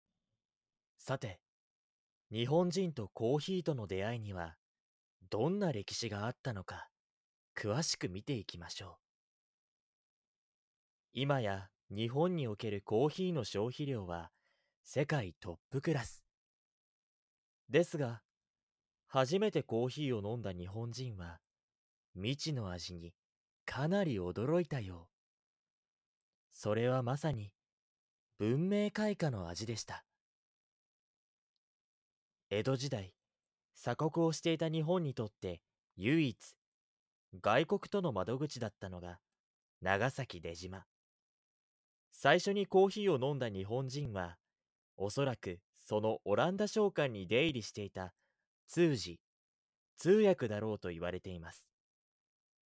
ボイスサンプル
ナレーション（日本人とコーヒーの歴史）